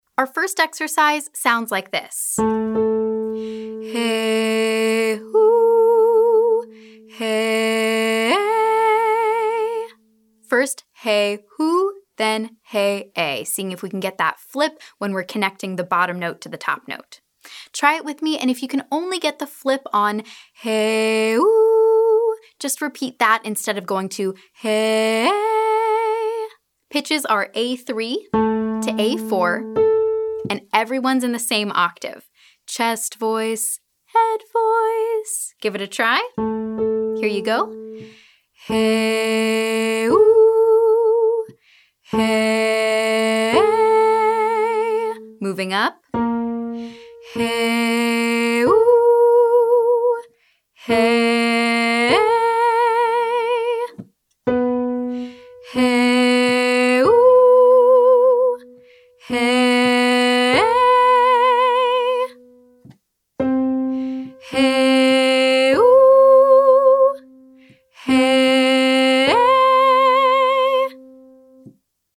It’s an abrupt transition from chest voice to head voice, resulting in something of a yodel effect.
• HEY-OO, HE-EY 1-8